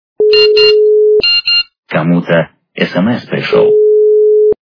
» Звуки » звуки для СМС » Звонок для СМС - Кому-то СМС пришло...
При прослушивании Звонок для СМС - Кому-то СМС пришло... качество понижено и присутствуют гудки.